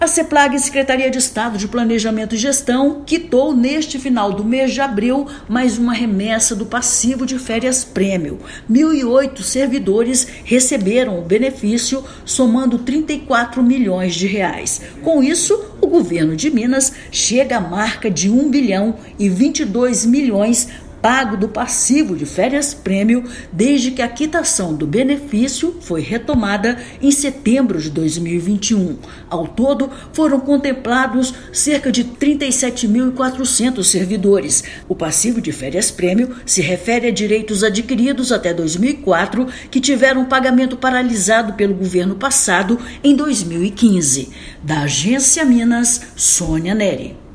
Remessa de pagamento do mês de abril foi depositada. Ouça matéria de rádio.